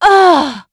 Valance-Vox_Damage_04.wav